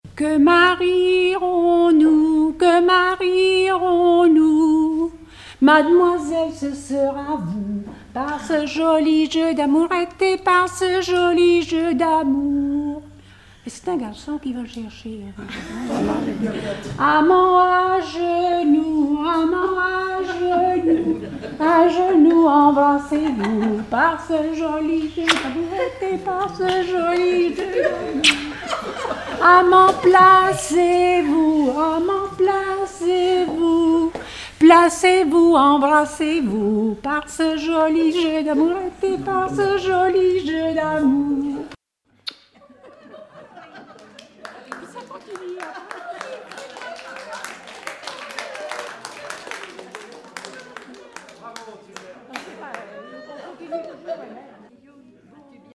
Rondes enfantines à baisers ou mariages
danse : ronde à marier
Pièce musicale inédite